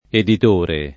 [ edit 1 re ]